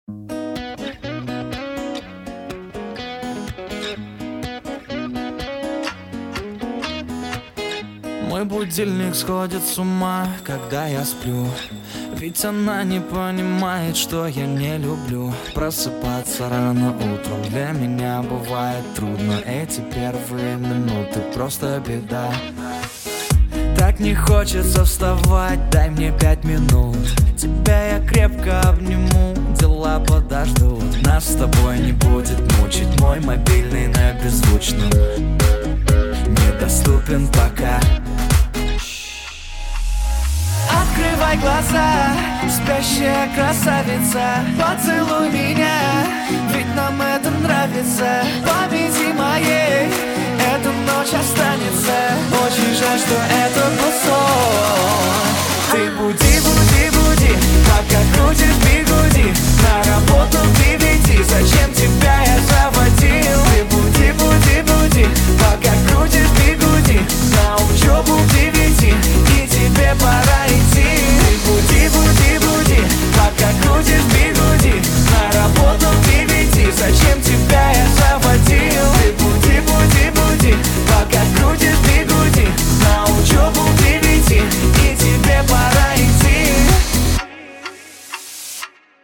красивые, спокойные, мужской вокал, гитара